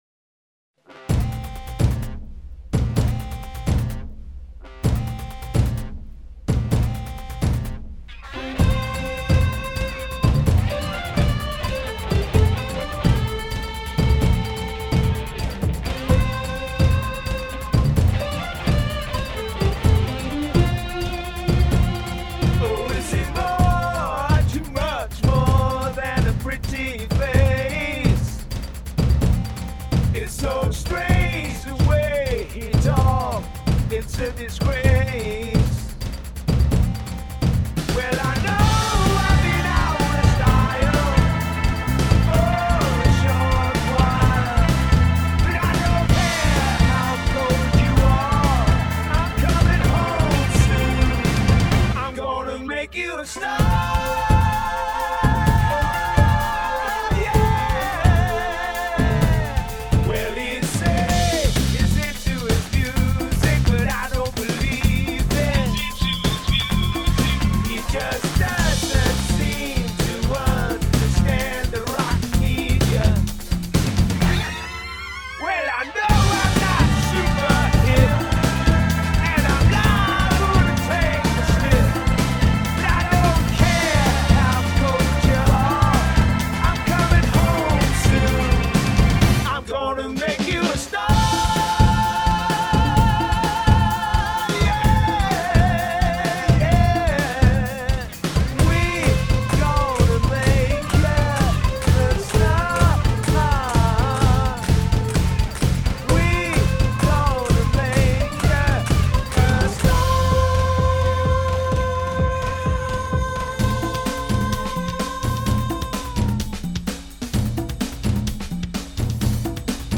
glam‑pop track